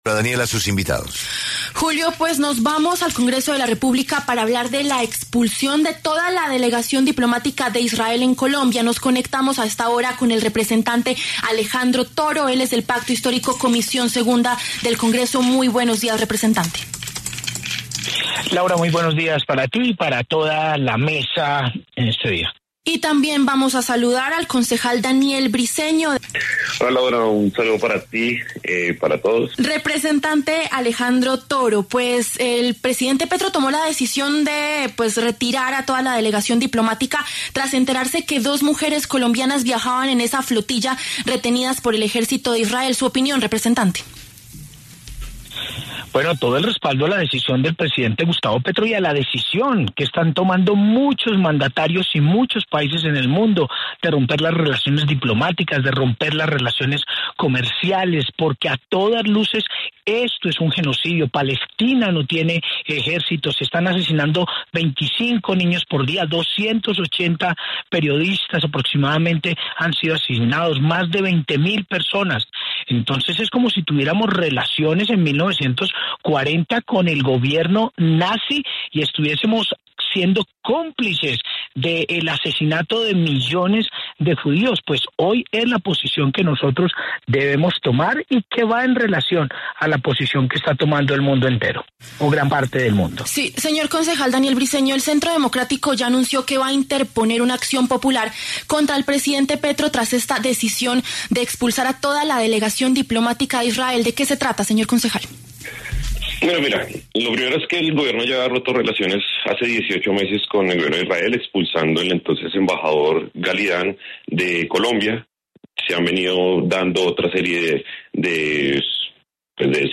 El representante del Pacto Histórico, Alejandro Toro, se enfrentó con Daniel Briceño, concejal de Bogotá y eventual aspirante a la Cámara de Representantes por el Centro Democrático. Ambos pasaron por los micrófonos de La W.